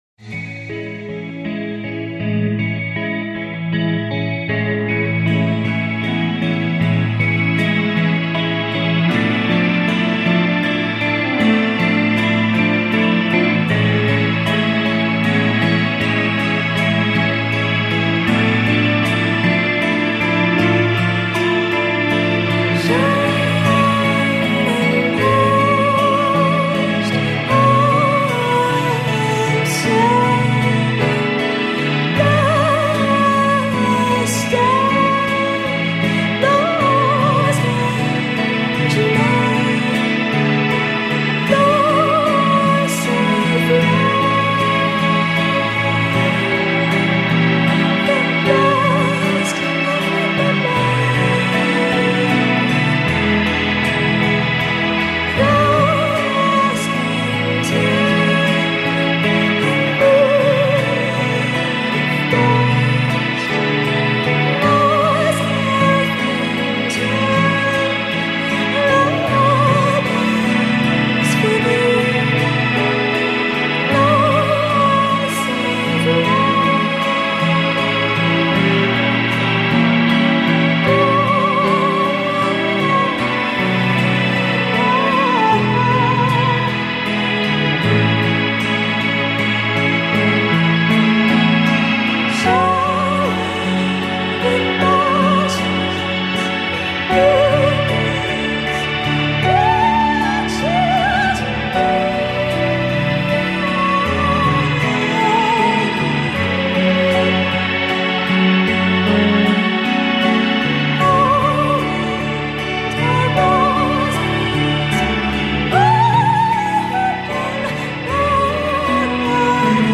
Cornering the market on glossolalia.
the effects laden guitar and soundscape
captivating vocals